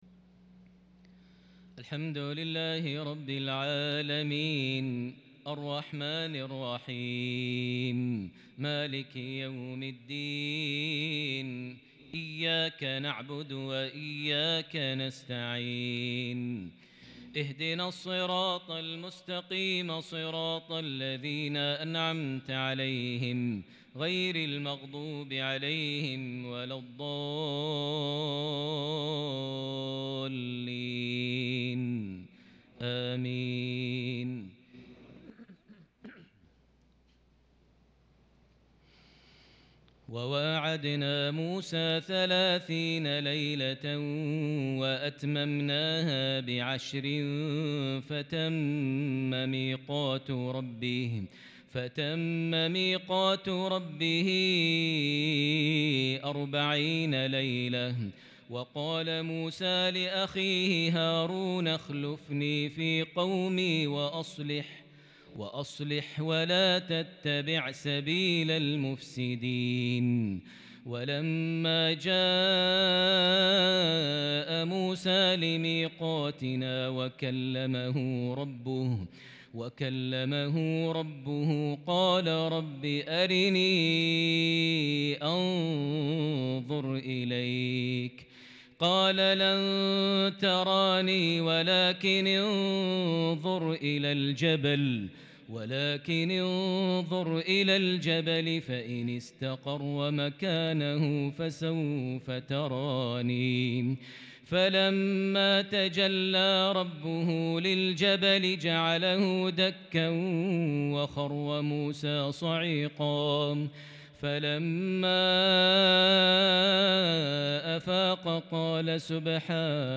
lsha 7-9-2021 prayer from Surah Al-Araf 142-153 > 1443 H > Prayers - Maher Almuaiqly Recitations